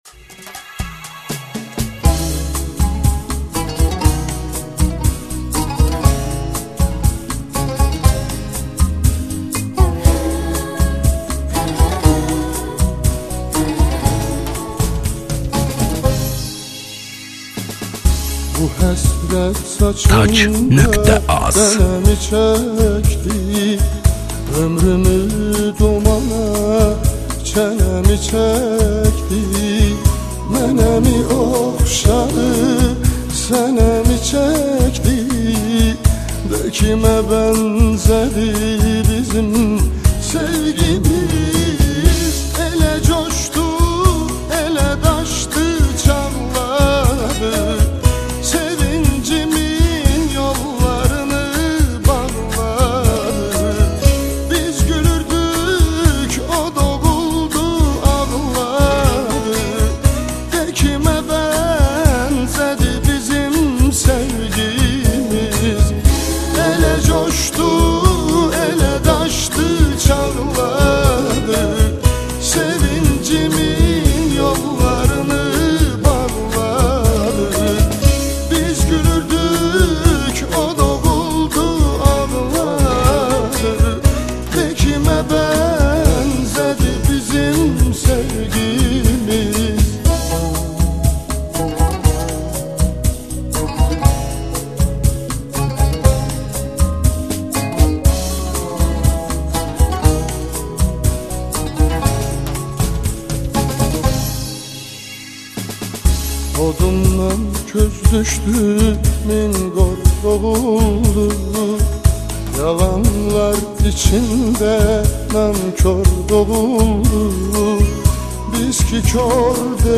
мейханы